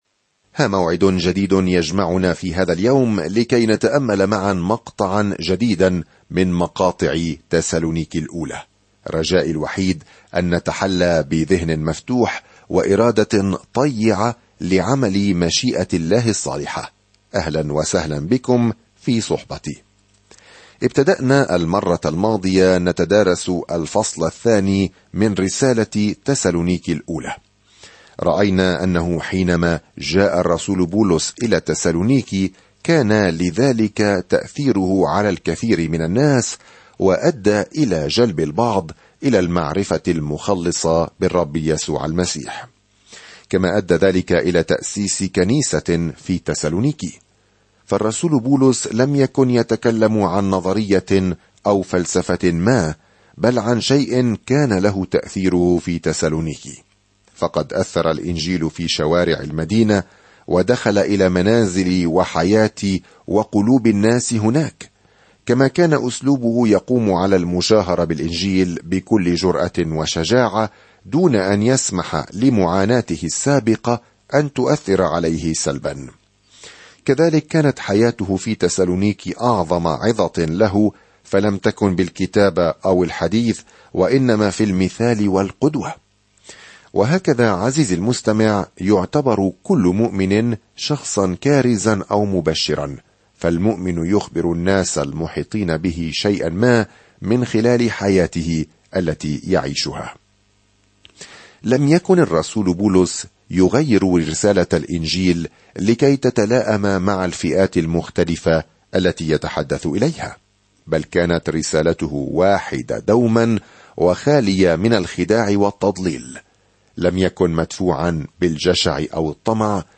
سافر يوميًا عبر رسالة تسالونيكي الأولى وأنت تستمع إلى الدراسة الصوتية وتقرأ آيات مختارة من كلمة الله.